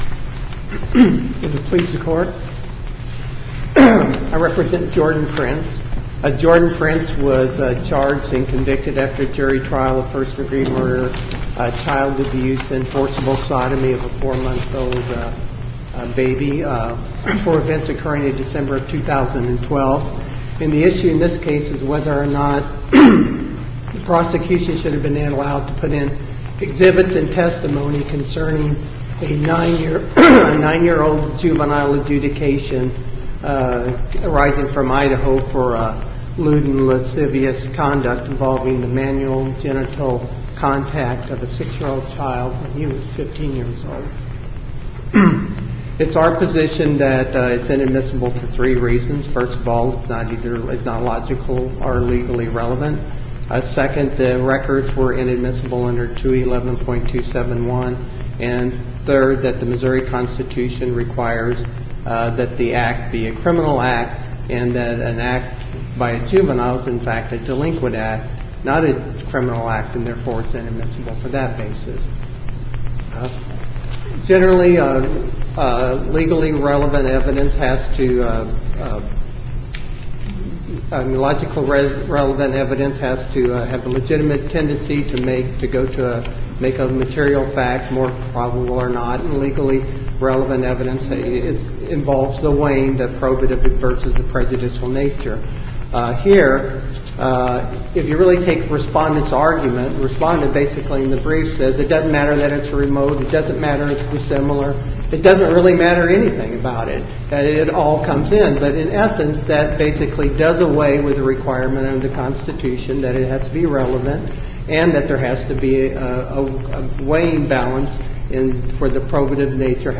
MP3 audio file of arguments in SC96107